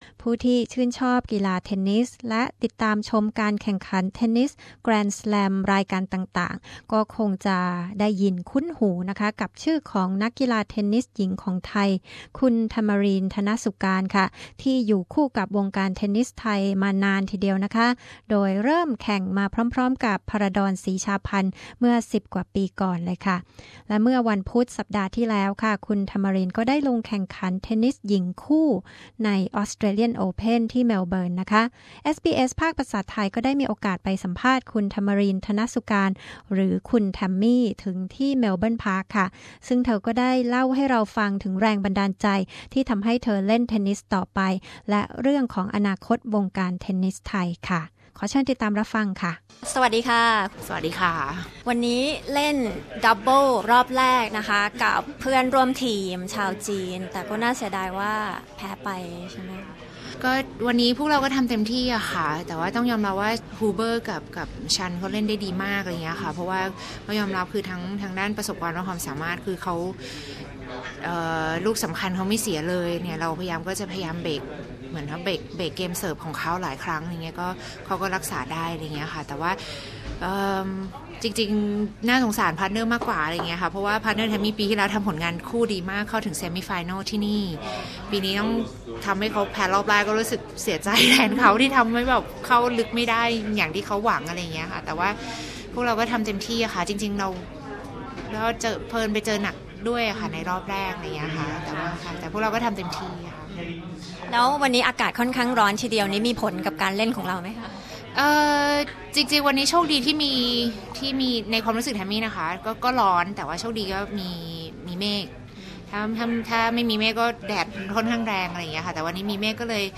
Tamarine Tanasugarn, a Thai veteran female tennis star, reveals about her motivation to keep playing tennis professionally and her views on the present Thai tennis scene. Tamarine talks to the Thai Program of SBS exclusively after her match at the Australian Open 2014 at Melbourne Park.